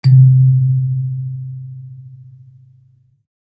kalimba_bass-C2-mf.wav